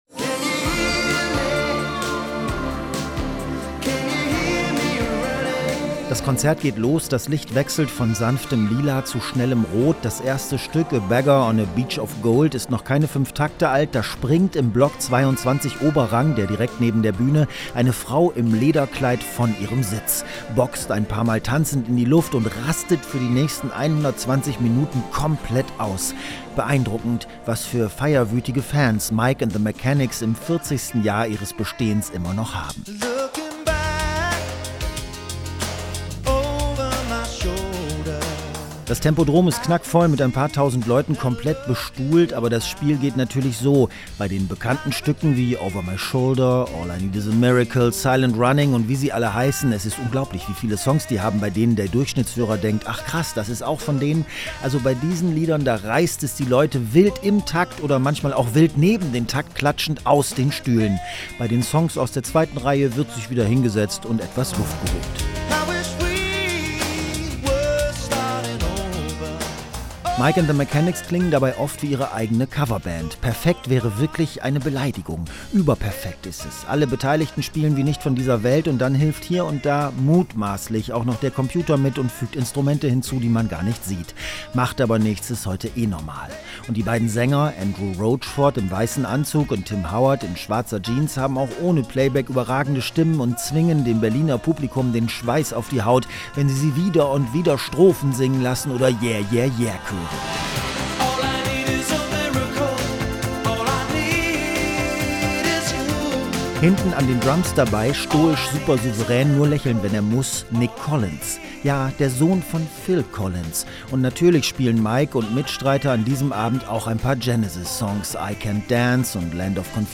Gitarrist Mike Rutherford wurde berühmt mit Genesis – und mit "Mike and the Mechanics". Am Sonntag begeisterte die Band ihre Fans im Berliner Tempodrom.